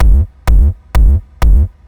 • Clicky Hard Kick Techno.wav
Clicky_Hard_Kick_Techno__DhL.wav